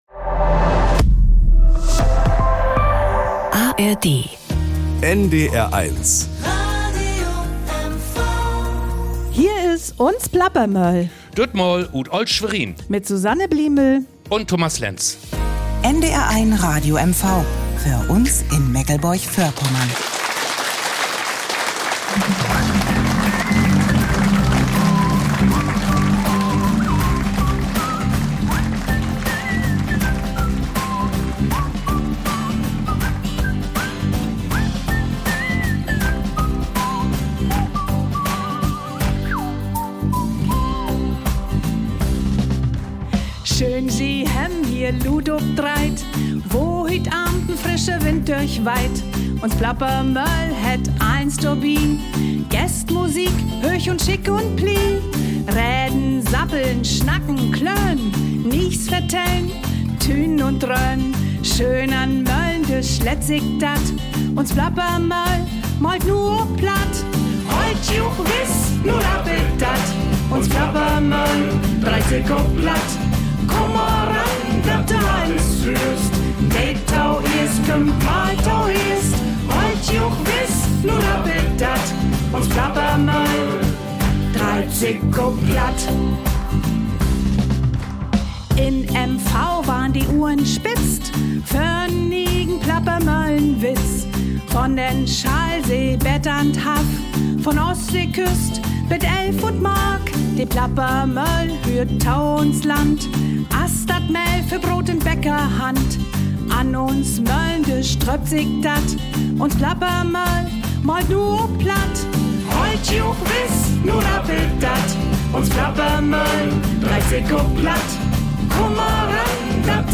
De Plappermoehl ut Olt Schwerin ~ Plattdeutsches aus MV Podcast